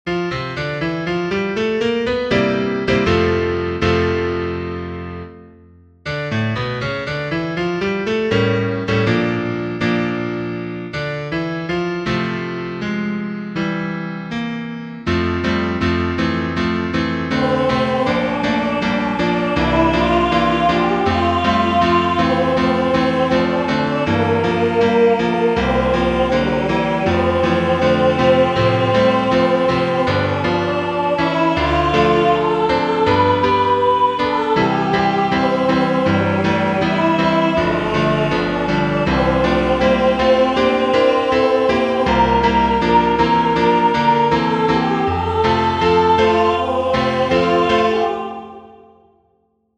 Vocal melody with piano accompaniment.
Key; F